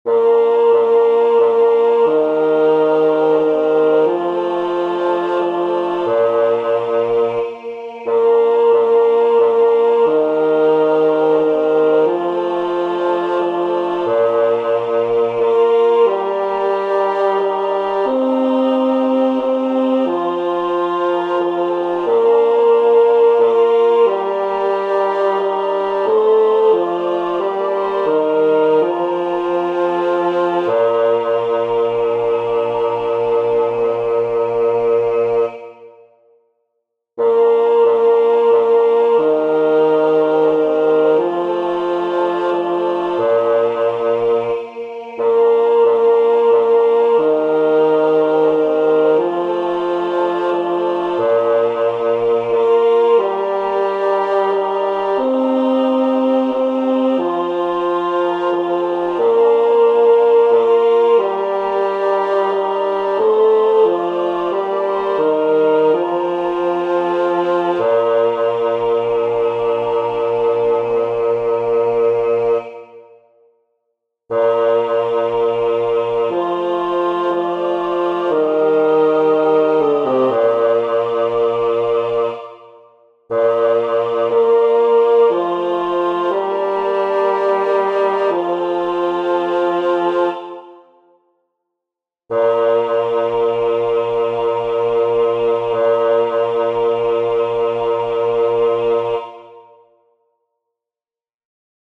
Para aprender la melodía os dejo los enlaces a los MIDIS según la primera versión que os expliqué más arriba, es decir, con el tenor a entrando a un tiempo de espera.
La melodía es muy sencilla y fácil de aprender.
ave_vera_virginitas-bajo.mp3